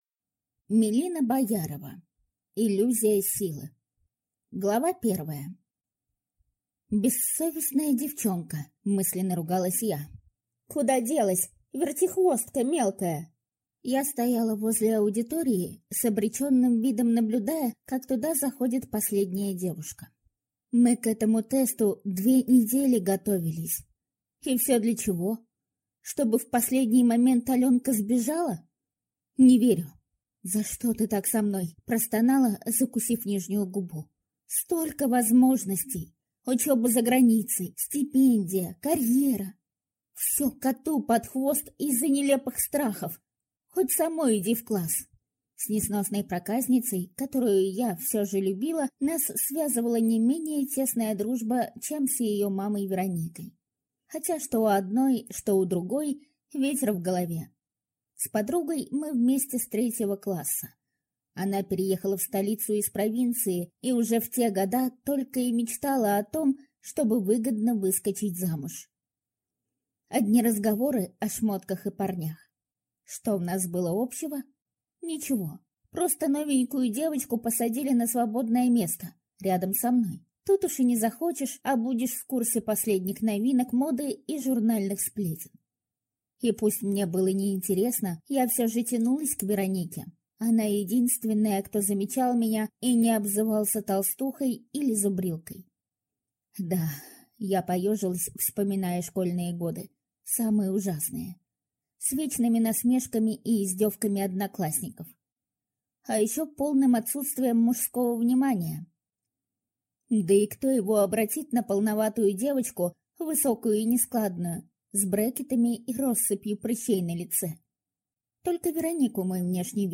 Аудиокнига Иллюзия силы | Библиотека аудиокниг